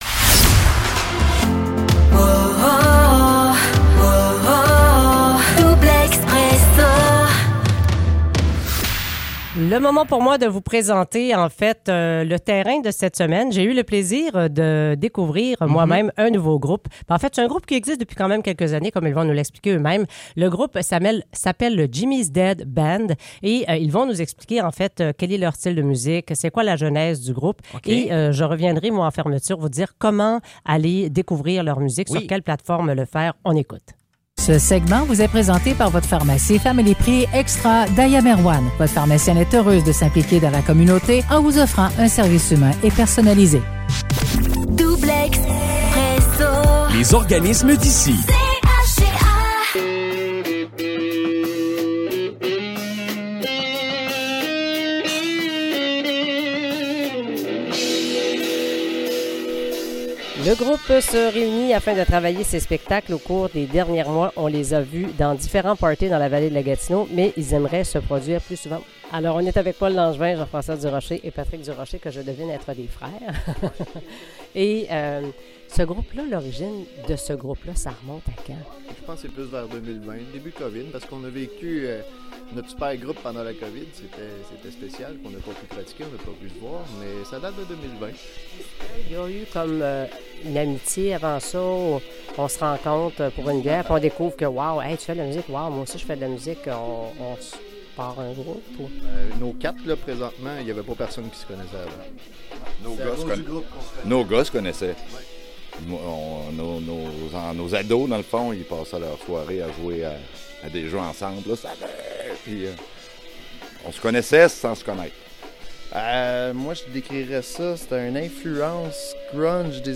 à la rencontre des membres du groupe rock local Jimmy's Dead Band lors d'une récente pratique.